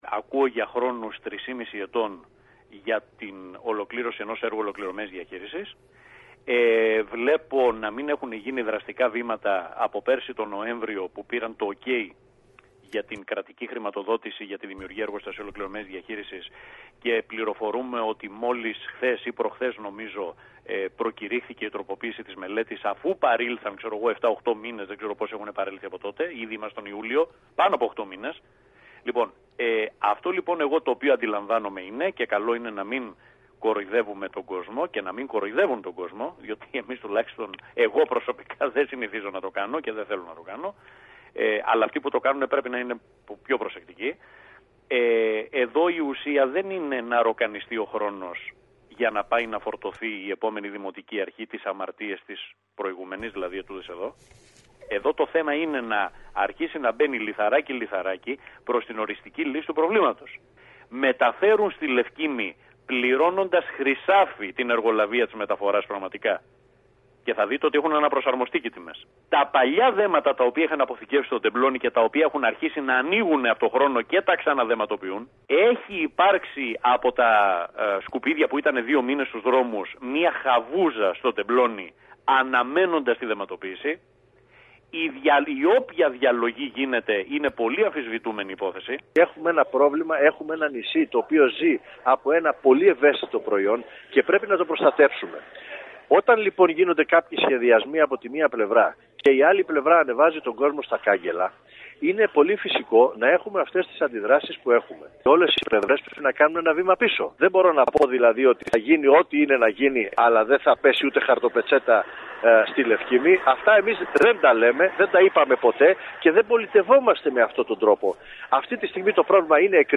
Περιφερειακοί σταθμοί ΚΕΡΚΥΡΑ
Αρνητική είναι η εικόνα με τα ΜΑΤ στη Λευκίμμη, δήλωσε μιλώντας στην ΕΡΤ ο βουλευτής ΣΥΡΙΖΑ Κ. Παυλίδης, ο οποίος έκανε λόγο για την ανάγκη κοινωνικής ανοχής.
ρεπορτάζ